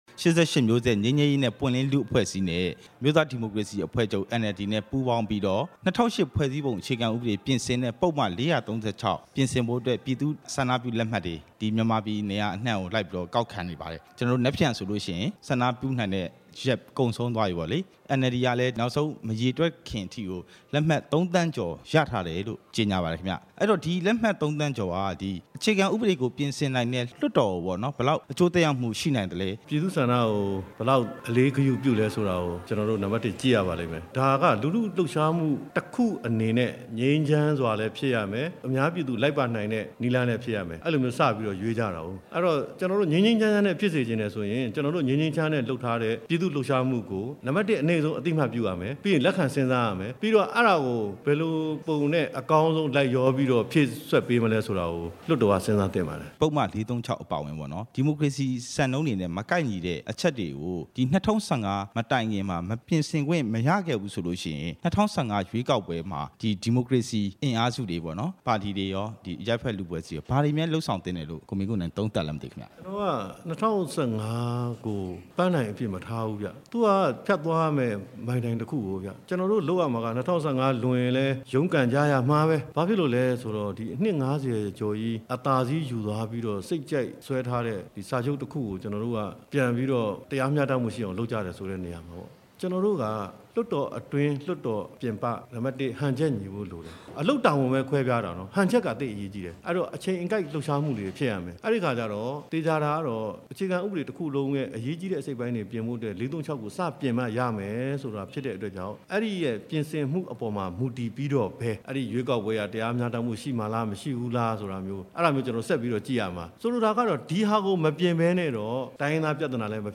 ကိုမင်းကိုနိုင်နဲ့ တွေ့ဆုံမေးမြန်းချက်